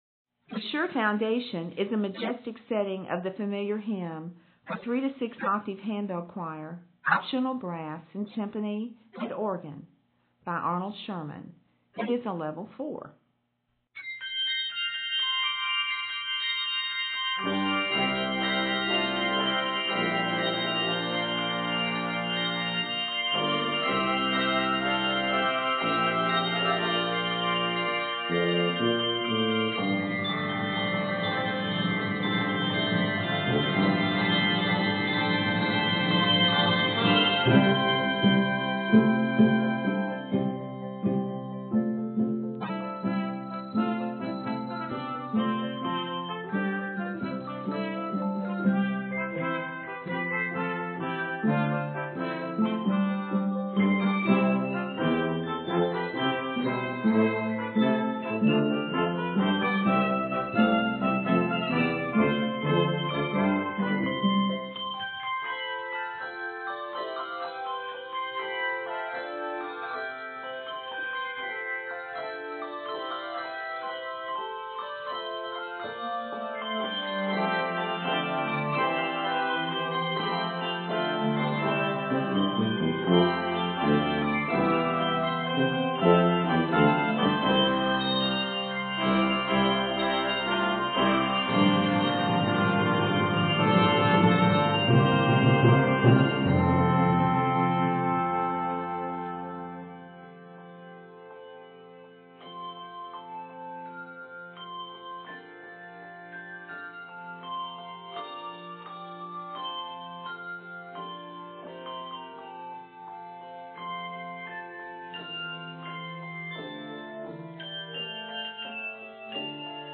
spectacular, energetic setting for 3-6 octave handbell choir